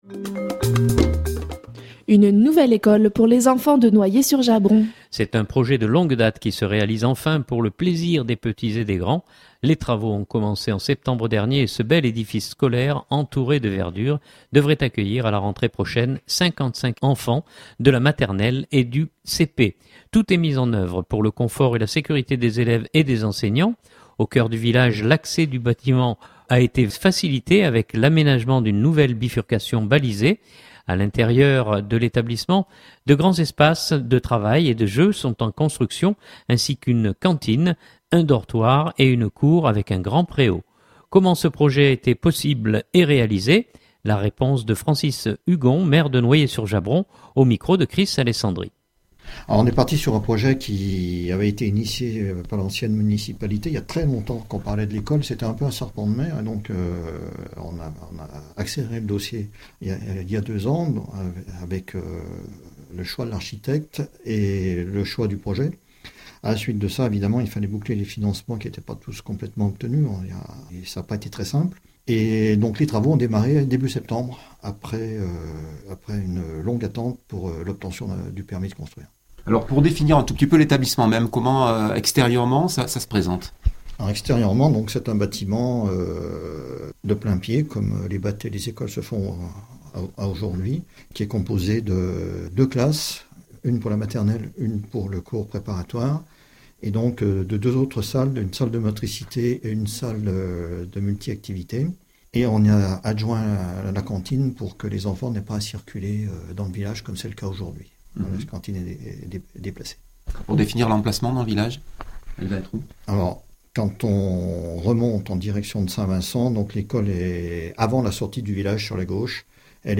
Comment ce projet a été possible et réalisé? La réponse de François Hugon maire de Noyers sur Jabron